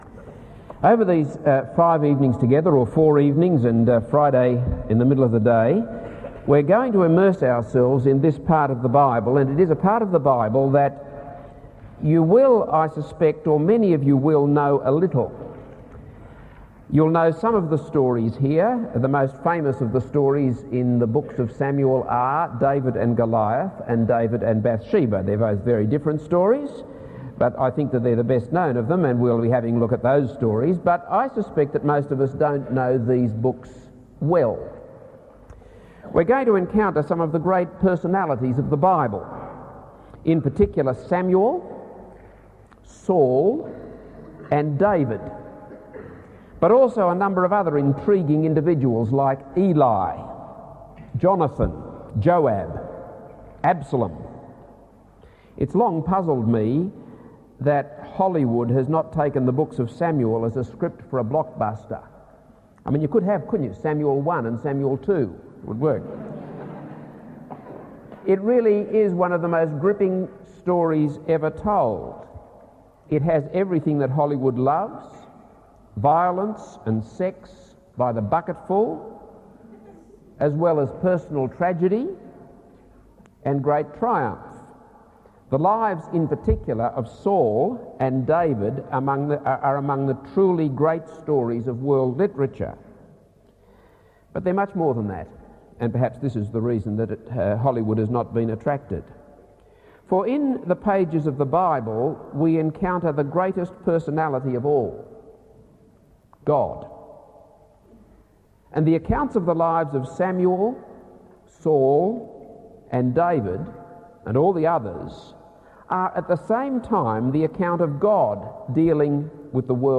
This is a sermon on 1 Samuel 1-2.